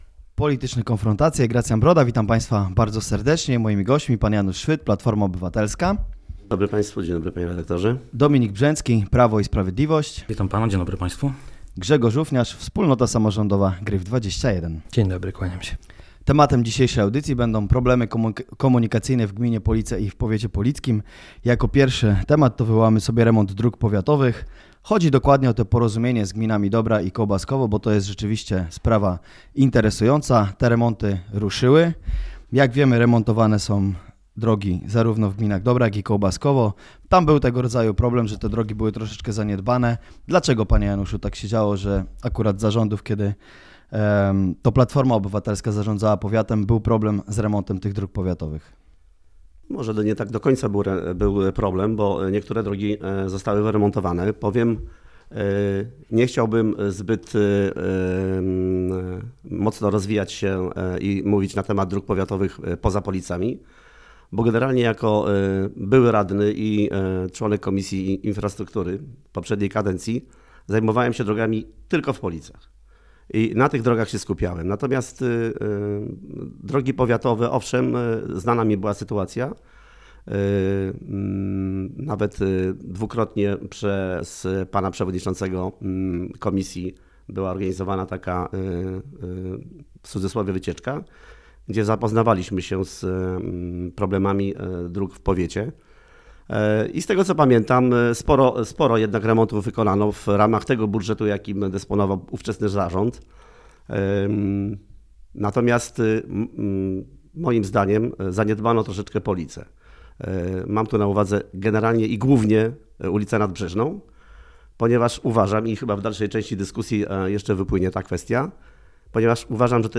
Dzisiaj na łamach Wirtualnych Polic publikujemy audycję "Polityczne Konfrontacje", podczas której z naszymi gośćmi rozmawialiśmy o problemach komunikacyjnych na terenie gminy Police oraz Powiatu Polickiego. W programie rozmawiano o: remoncie ulicy Nadbrzeżnej, Asfaltowej, petycji w sprawie budowy ronda u zbiegu ulicy Piłsudskiego i Wkrzańskiej, remoncie wiaduktu przy ul. Kuźnickiej.